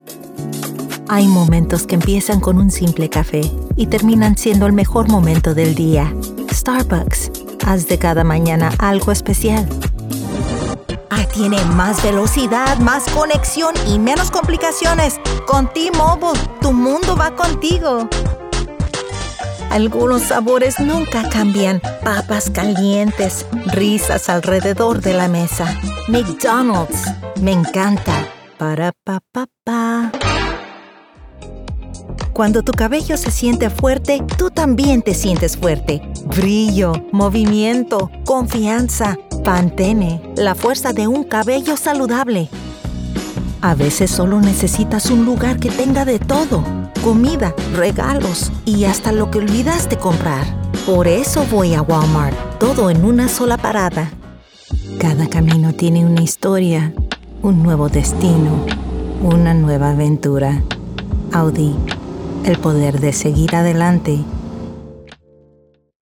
Adult (30-50)